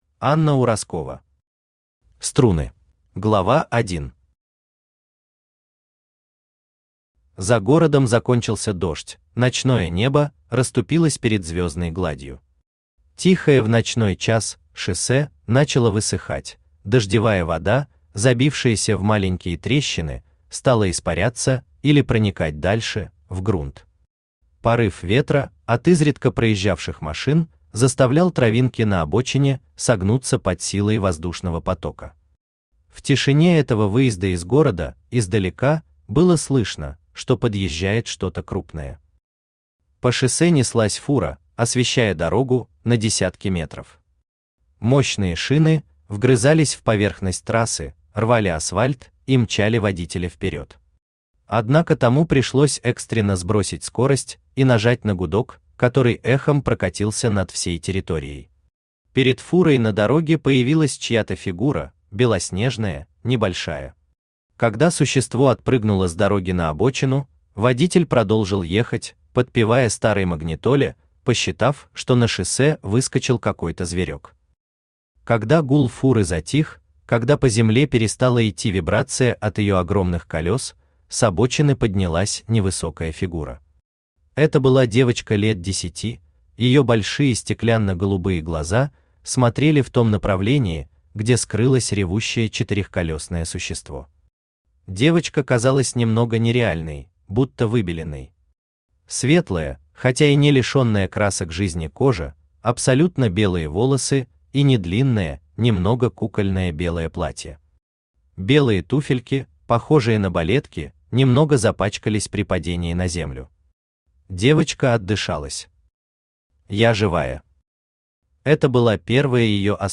Аудиокнига Струны | Библиотека аудиокниг
Aудиокнига Струны Автор Анна Ураскова Читает аудиокнигу Авточтец ЛитРес.